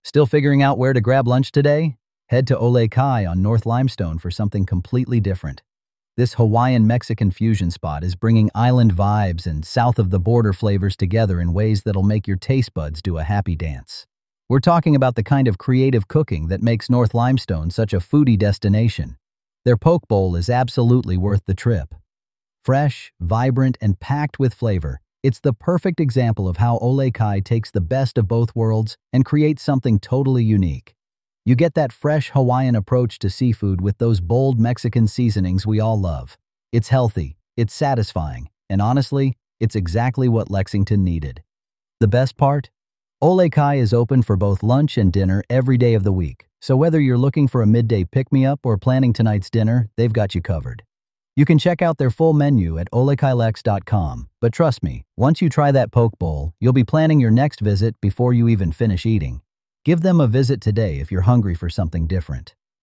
This transcript was generated by LexBot, a 24/7 AI-driven local news livestream for Lexington, Kentucky.
Voice synthesis via ElevenLabs; script via Claude.